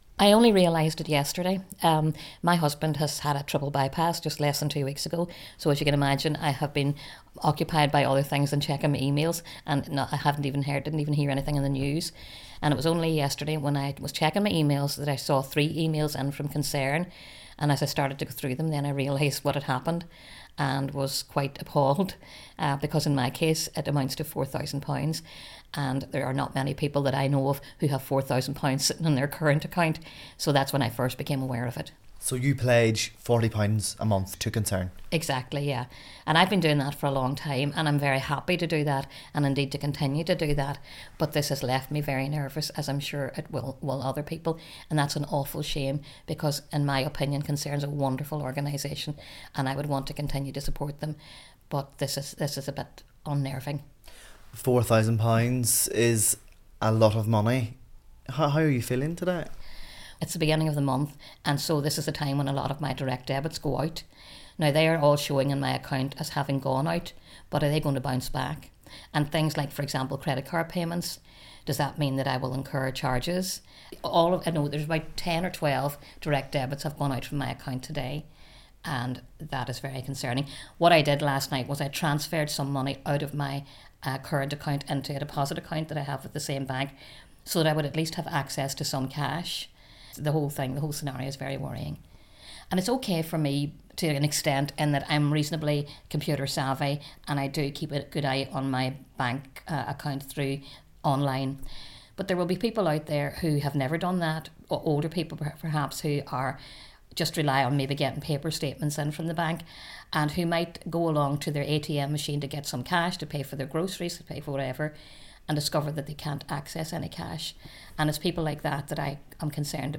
A Derry woman's been telling how £4,000 was taken from her bank account following a credit card error by the charity Concern. The charity apologised for taking 100 times the normal donation amount from some of its supporters. 6,000 people in total have been affected in Northern Ireland.